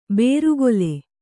♪ bērugole